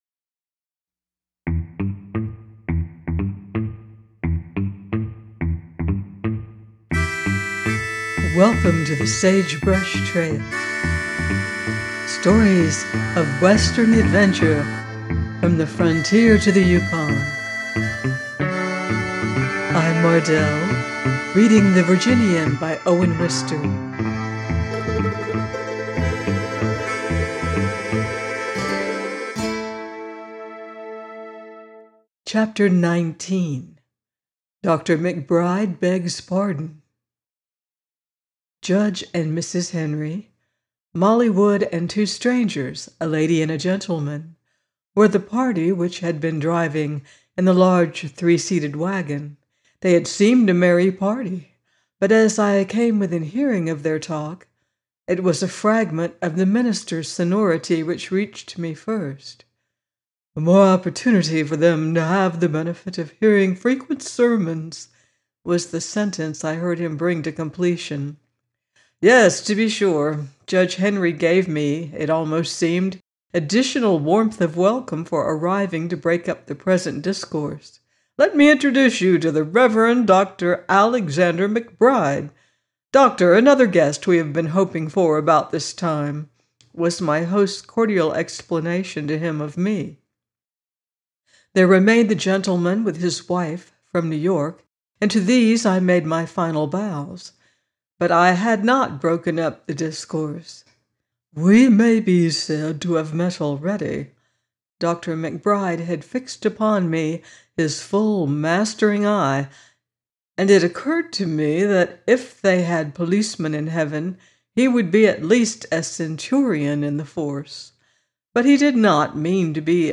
The Virginian 19 - by Owen Wister - audiobook